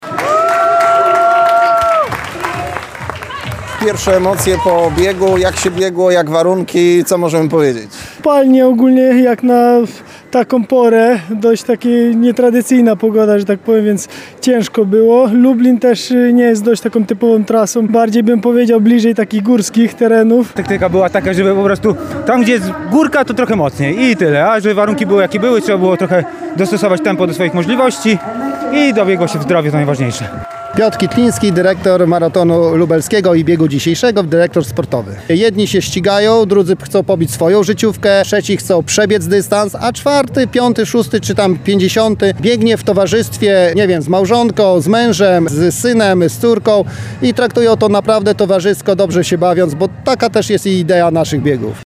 CZYTAJ: Marcin Komenda: Trener ostrzega nas przed meczami z teoretycznie słabszymi rywalami – Dość taka nietradycyjna pogoda, więc ciężko było – mówi w rozmowie z Radiem Lublin jeden z uczestników wydarzenia.